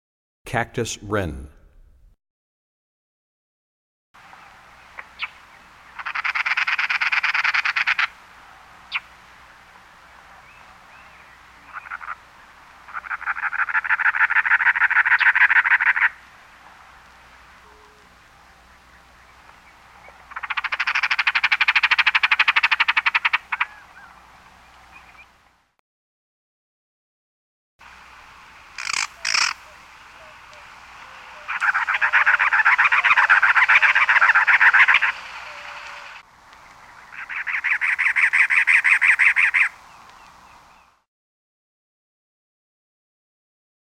18 Cactus Wren.mp3